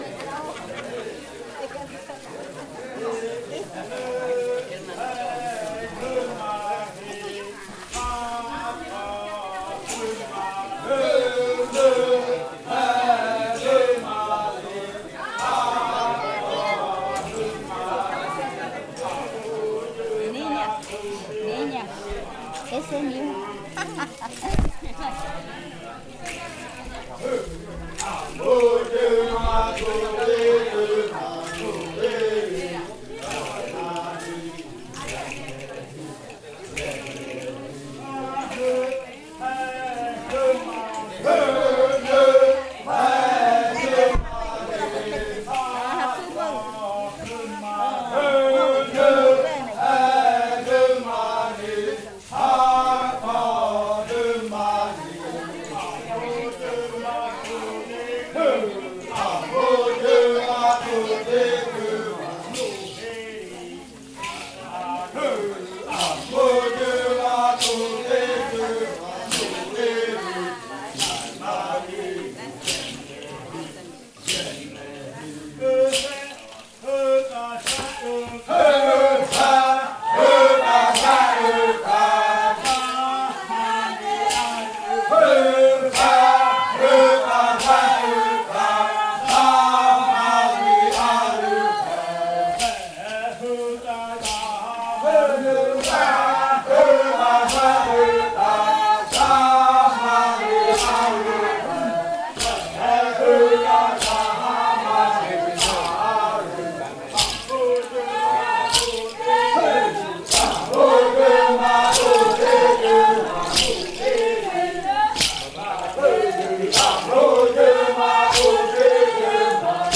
Canto de la variante muruikɨ
con el grupo de cantores bailando en Nokaido. Este canto hace parte de la colección de cantos del ritual yuakɨ murui-muina (ritual de frutas) del pueblo murui, colección que fue hecha por el Grupo de Danza Kaɨ Komuiya Uai con el apoyo de un proyecto de extensión solidaria de la UNAL, sede Amazonia.